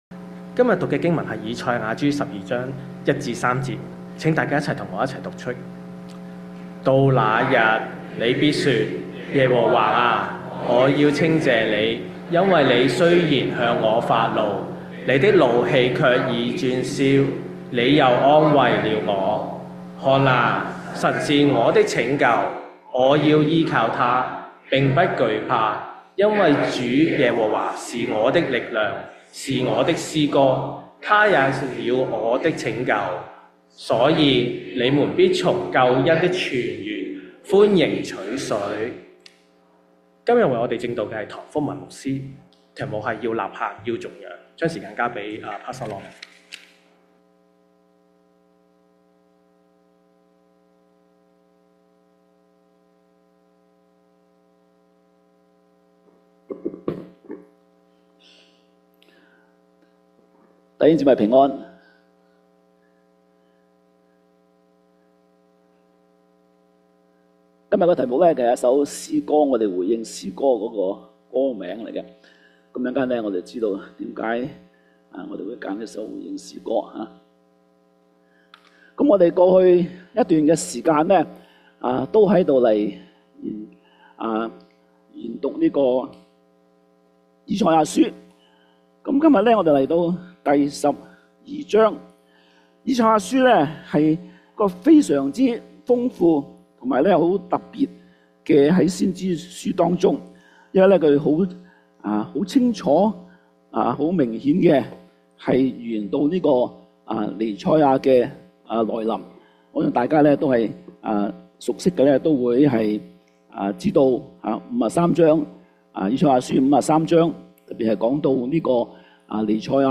粵語堂主日崇拜-《要吶喊-要頌楊》-《以賽亞書-12-1-3-節》.mp3